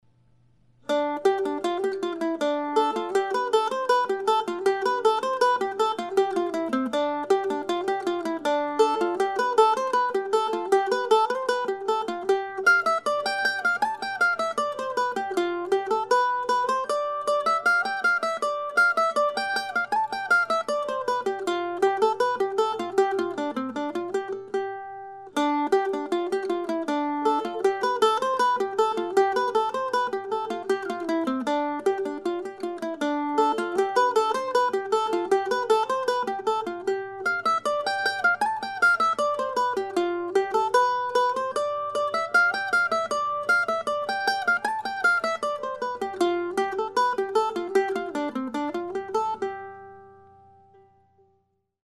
Early in 2016 I started writing short pieces modeled after the Divertimentos that James Oswald composed and published in the 1750s in London.
I've been playing them before or after Oswald's own pieces during my solo mandolin coffee house gigs this year and now my plan is to turn them into a small book that I intend to have available at the Classical Mandolin Society of America annual convention in Valley Forge, early next month.